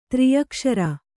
♪ triyakṣara